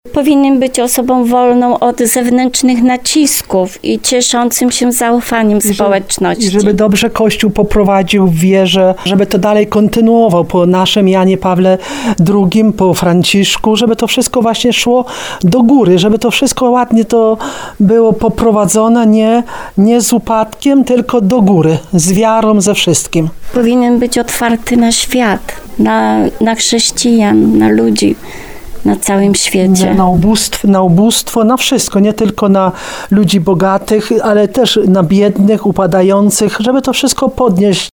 Mieszkanki Paszczyny o pierwszych wrażeniach po wyborze papieża
Członkowie Akcji Katolickiej przy parafii Matki Bożej Różańcowej w Paszczynie kilka minut po ogłoszeniu Habemus Papam komentowali w Radiu RDN Małopolska, że nowego papieża czekają duże wyzwania.